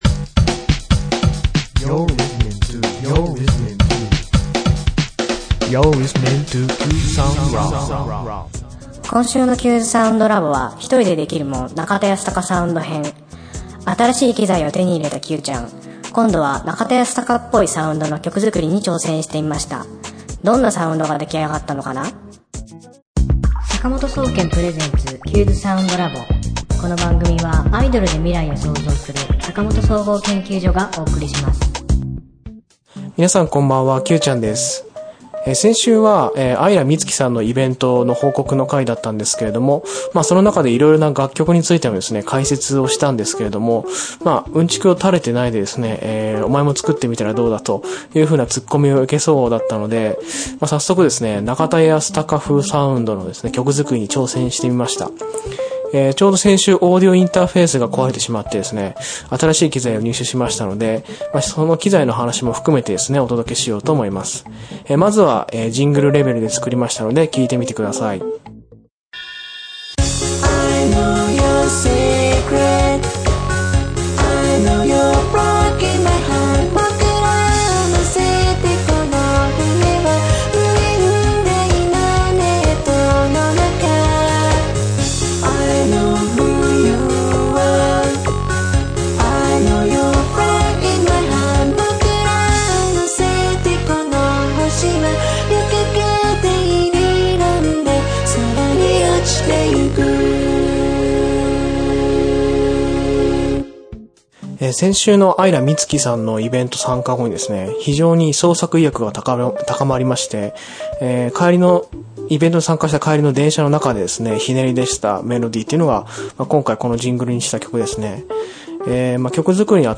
今週のテーマ：新しい機材とジングルのお話 新しい機材を手に入れたので、中田ヤスタカっぽいサウンドのジングル作りに挑戦してみました。